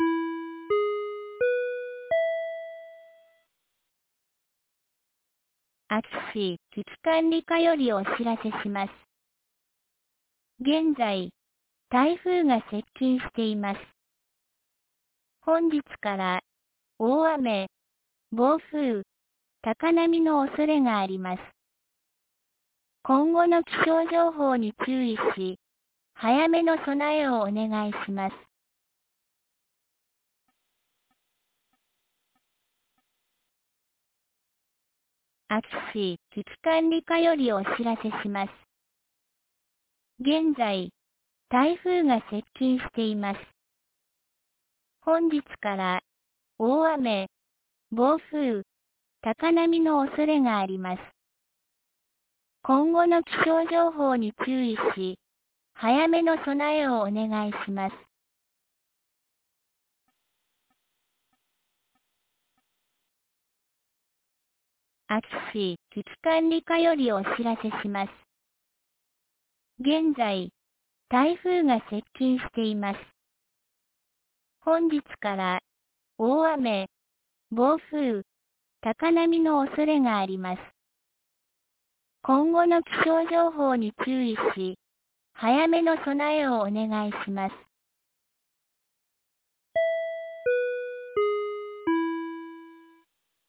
2022年09月18日 09時01分に、安芸市より全地区へ放送がありました。